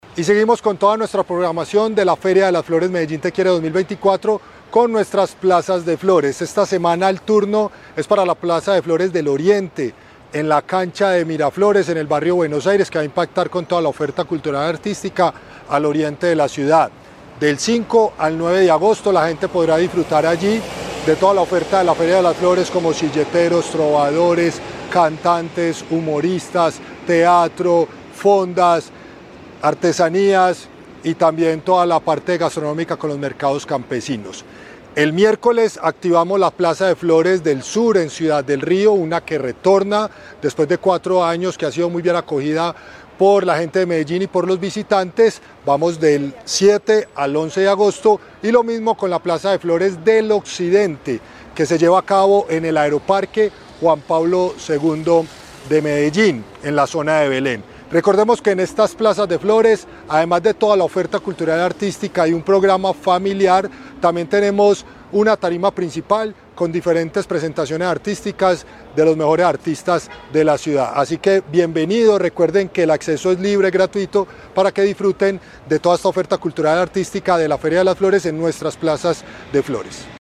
Palabras de Cristian Cartagena, subsecretario Arte y Cultura Tres nuevas Plazas de Flores se suman esta semana a las dos que abrieron el pasado viernes 2 de agosto en diferentes sitios de la ciudad.